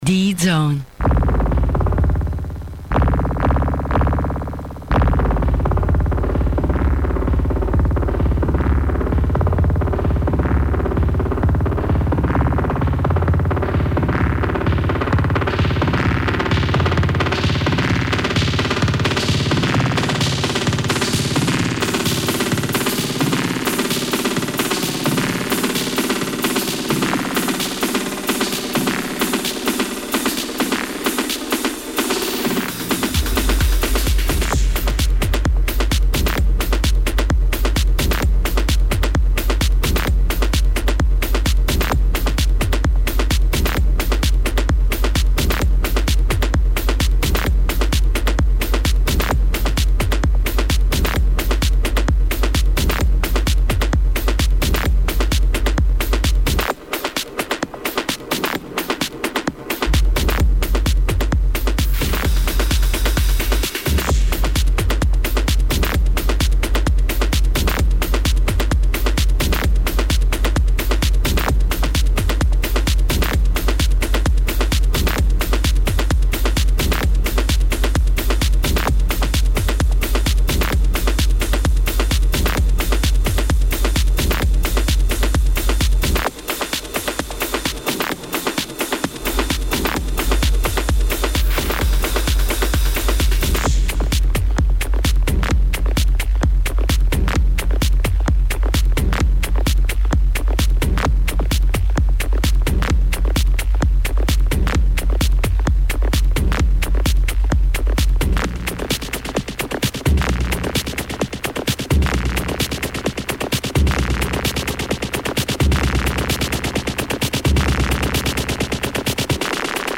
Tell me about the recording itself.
Second hour dedicated to Djset Onair Live!